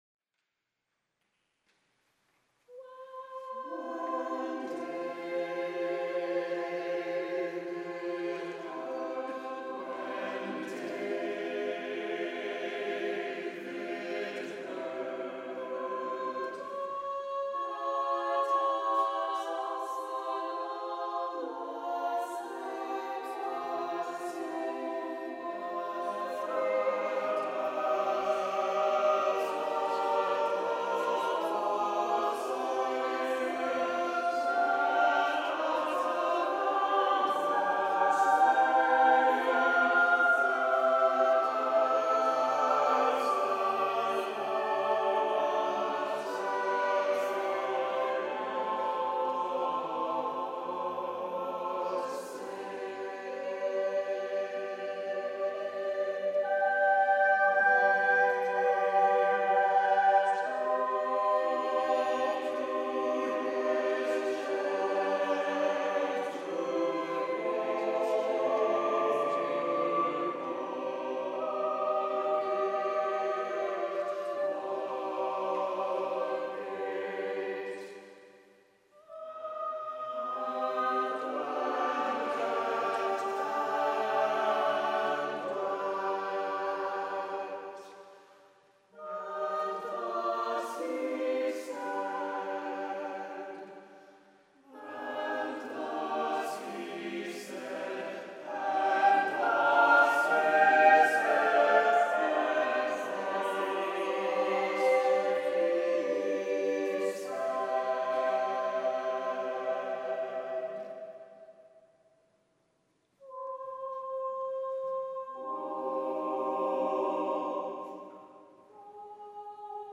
Samples of the choir from this program!